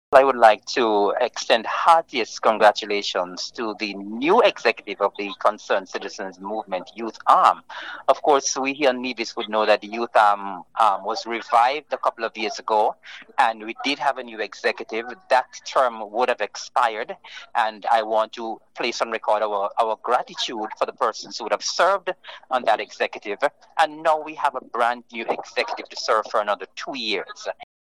The VONNEWSLINE recently spoke with local Minister of Youth and Sports et al., Hon. Eric Evelyn who made this comment in congratulating the new committee members: